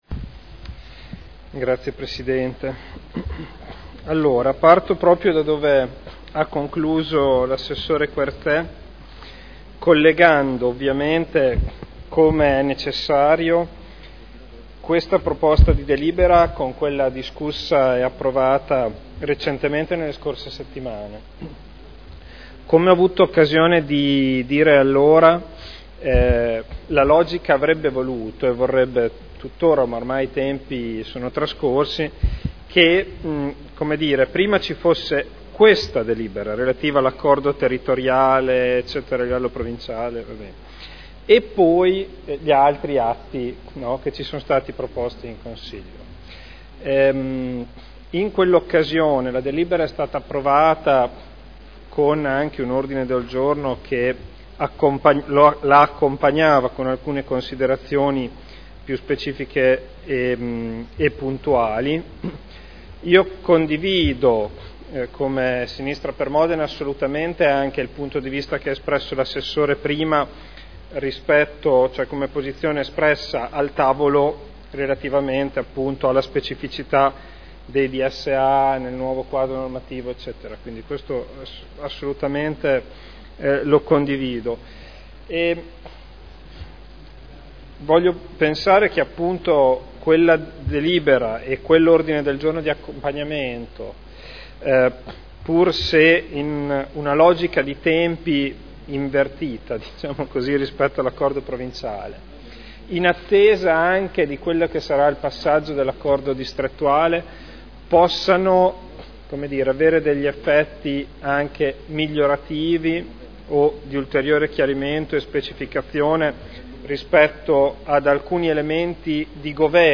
Federico Ricci — Sito Audio Consiglio Comunale
Dichiarazione di voto.